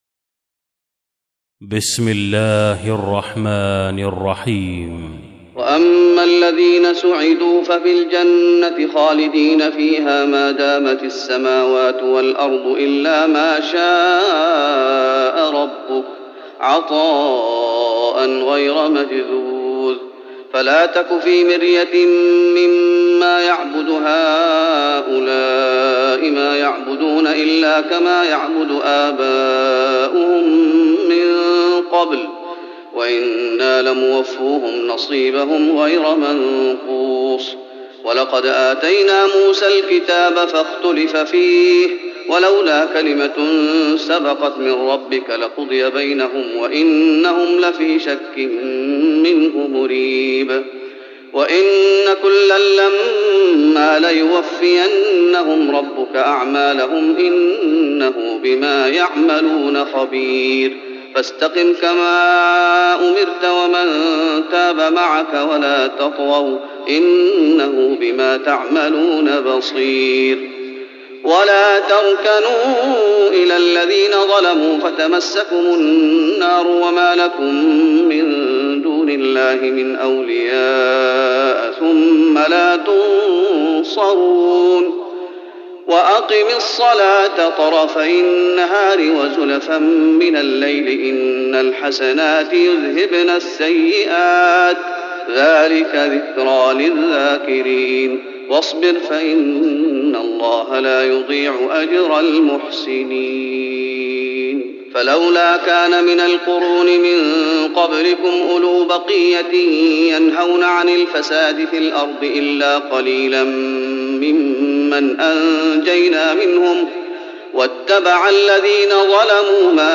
تراويح رمضان 1415هـ من سورة هود (108-123) Taraweeh Ramadan 1415H from Surah Hud > تراويح الشيخ محمد أيوب بالنبوي 1415 🕌 > التراويح - تلاوات الحرمين